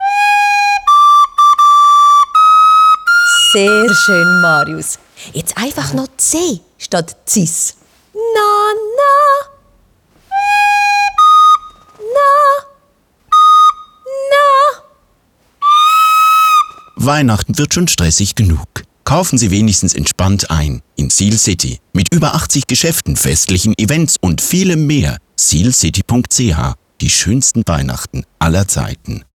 Neue Radiospots für das Einkaufszentrum Sihlcity.